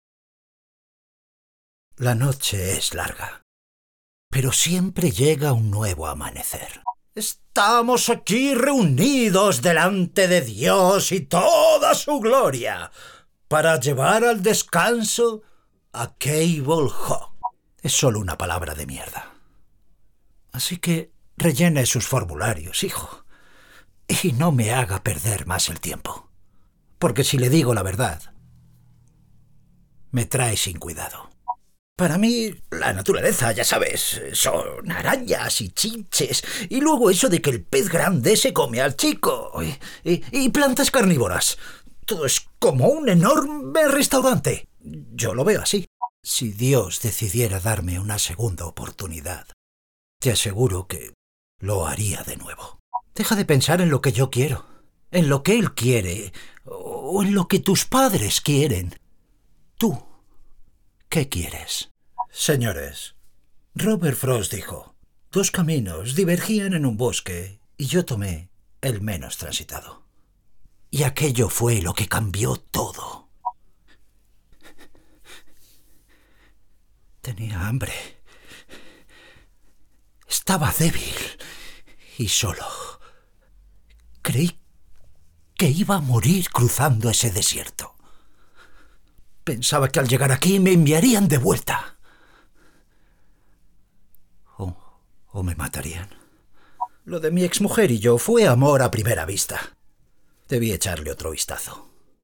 2025 Demos